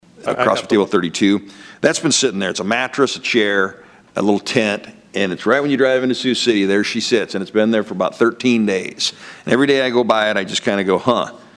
CITY POLICE CHIEF REX MUELLER DELIVERED HIS MOST RECENT REPORT ON THE OPERATIONS OF THE TASK FORCE ON THE HOMELESS TO THE SIOUX CITY COUNCIL THIS WEEK.